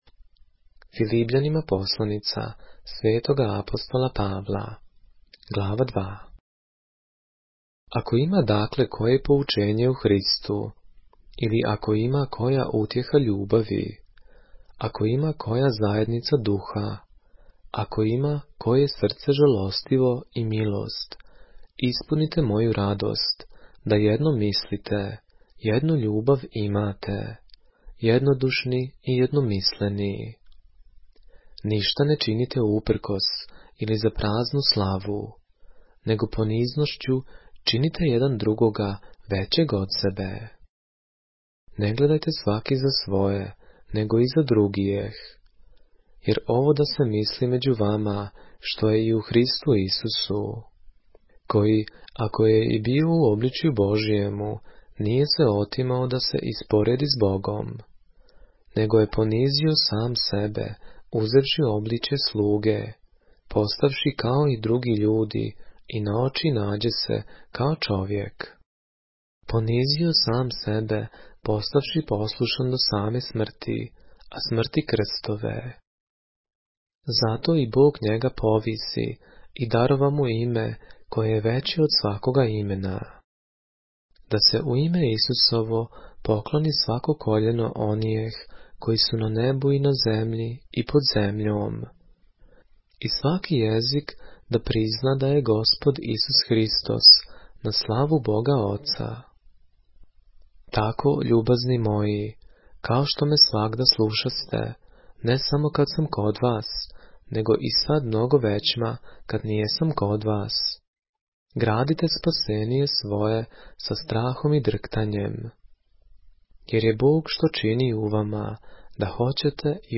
поглавље српске Библије - са аудио нарације - Philippians, chapter 2 of the Holy Bible in the Serbian language